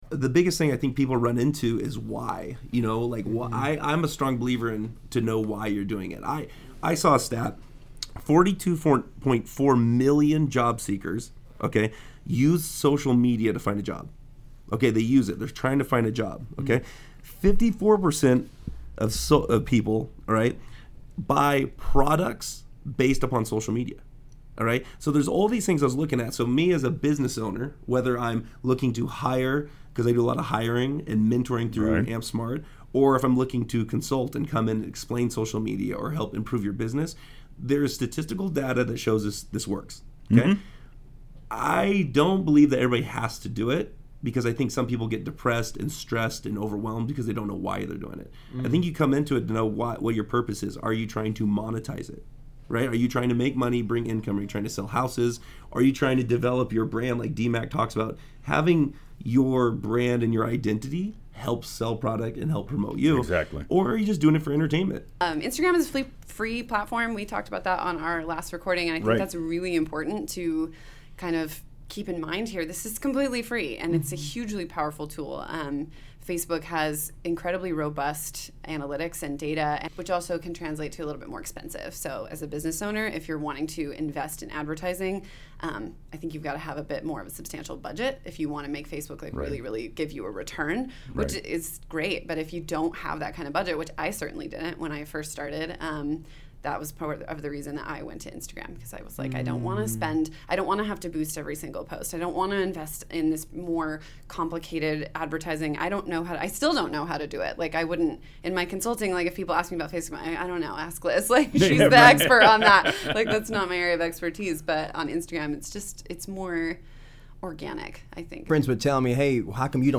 We invited three incredible Idaho-based business professionals who use Instagram as their primary marketing platform to speak on the subject and answer our questions. Come learn how social media has acted as an extreme catalyst in these social influencer’s careers and what you can do to grow your own brand and capitalize on your personal influence!
INSTAGRAM PANEL TEASER | SHORT EPISODE | 00:04:05